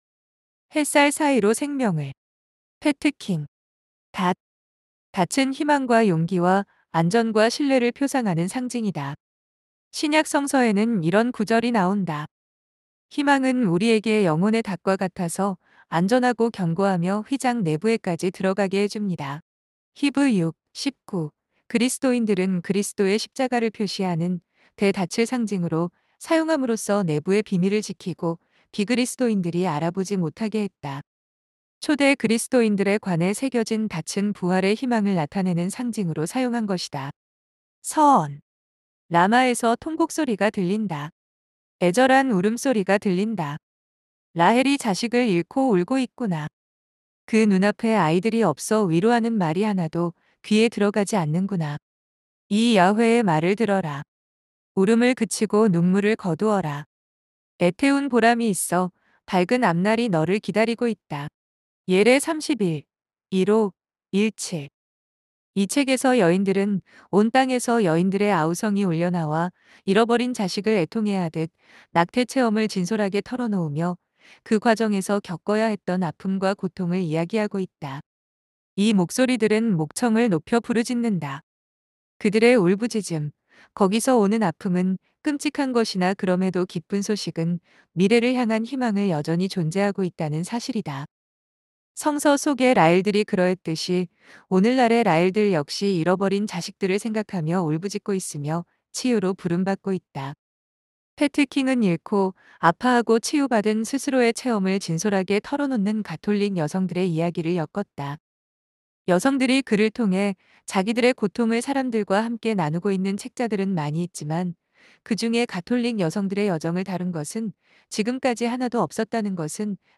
햇살사이로 생명을 - 오디오북